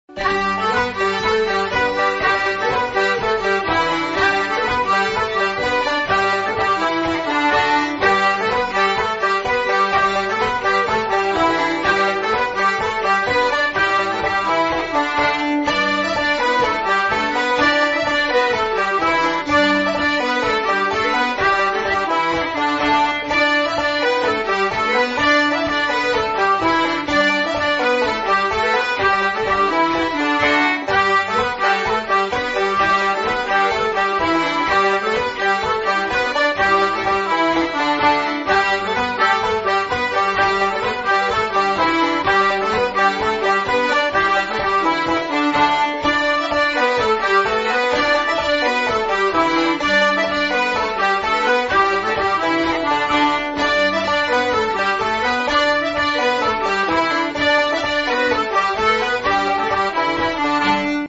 Polka en Sol, souvent jouée aussi en La majeur appellée parfois "la polka à 2 doigts" pour les violons ou les banjos